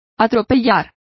Complete with pronunciation of the translation of trampling.